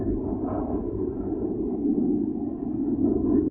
thruster.ogg